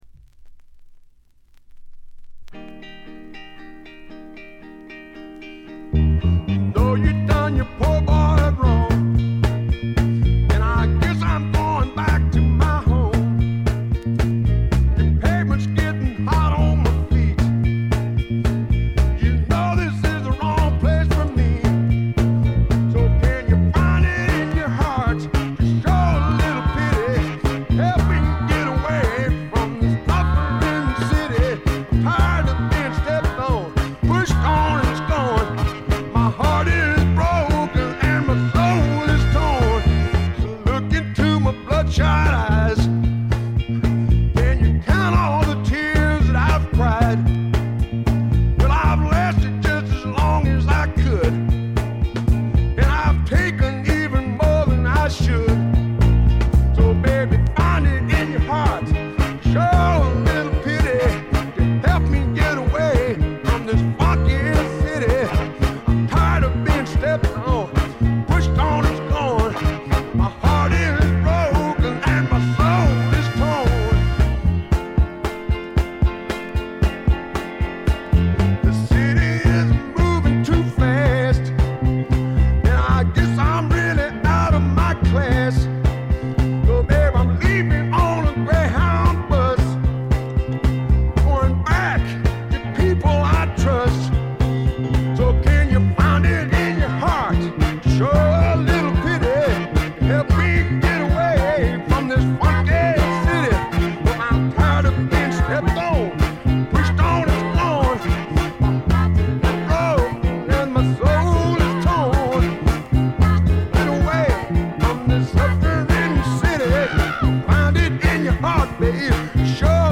テキサス産スワンプポップの名作。
試聴曲は現品からの取り込み音源です。